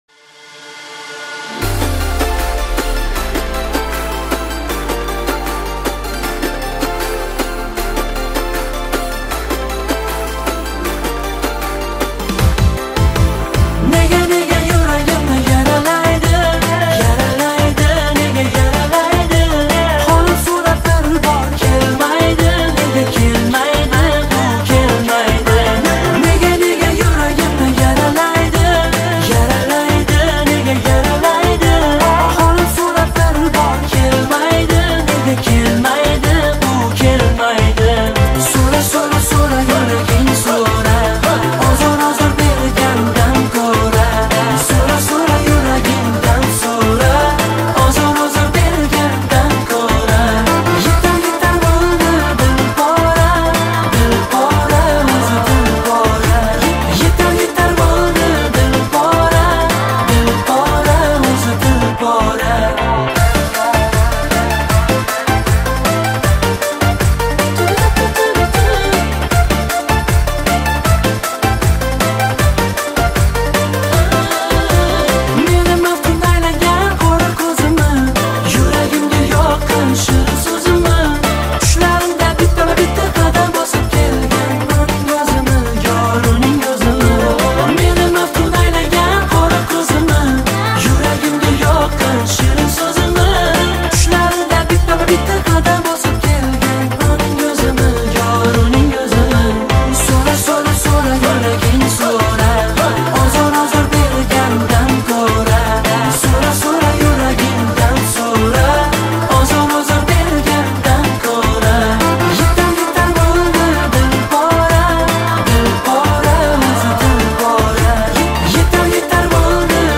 • Жанр: Индийские песни